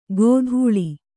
♪ gōdhūḷi